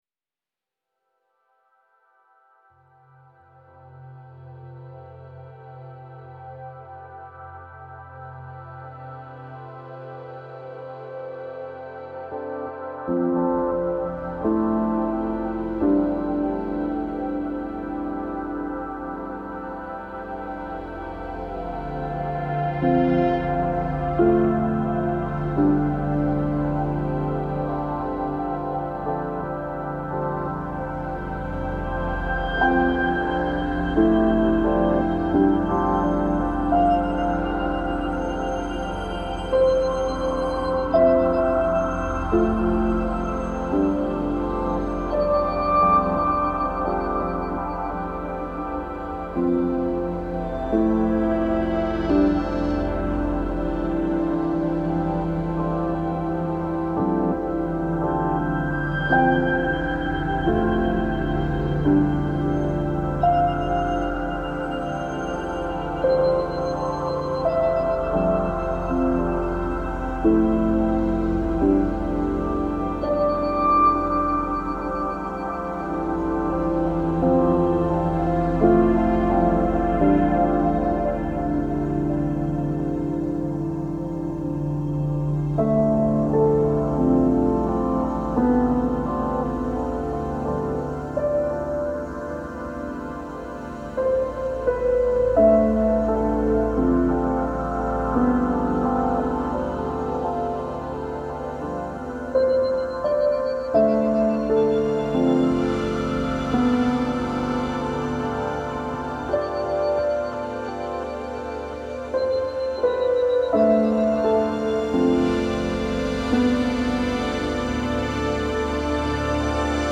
———————————————— Production Music Examples ————————————————